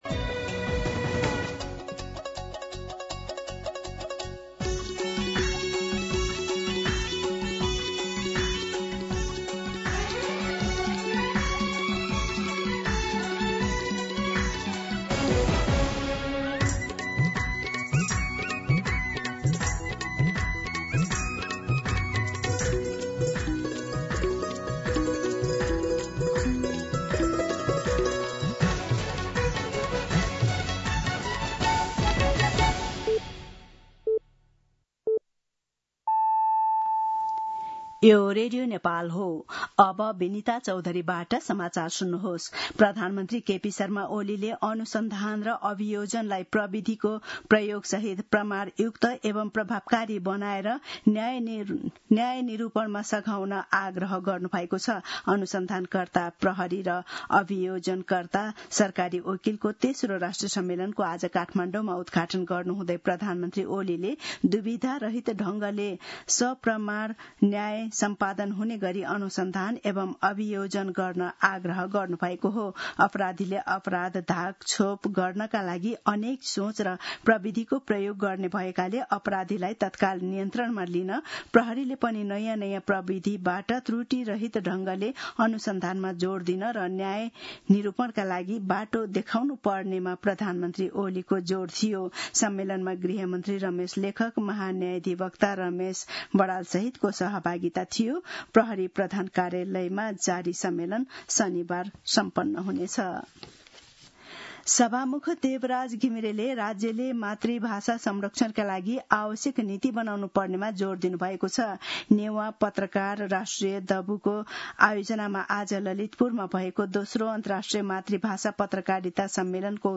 दिउँसो १ बजेको नेपाली समाचार : ३० जेठ , २०८२